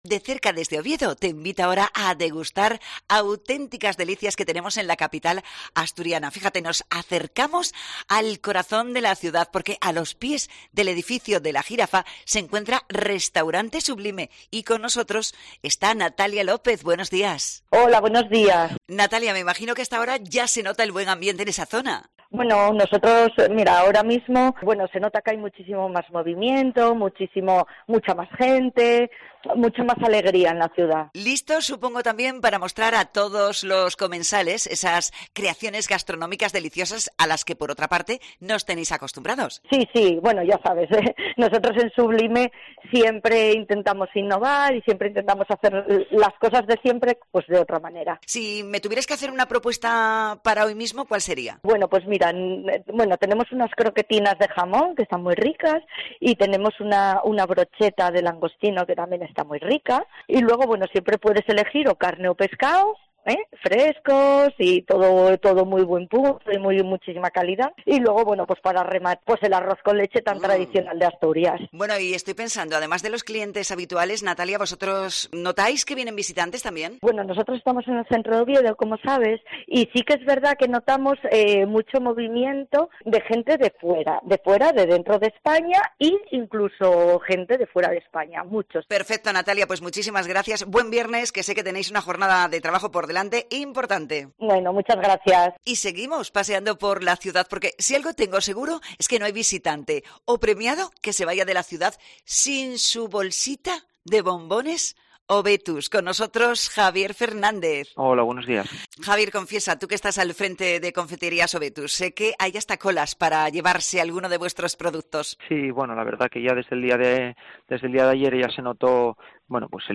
Las gaitas vuelven a sonar para recibir a los galardonados con los Premios Princesa: recorremos Oviedo, la ciudad por las que, estos días, van a pasear personajes destacados